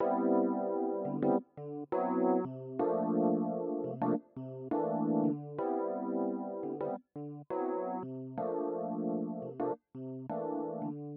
Hier nochmal, mit Width auf 0%, erst stereo, dann mono: Anhang anzeigen 106653 Und das ist eben exakt das, was passiert, wenn du den Mix in mono hörst.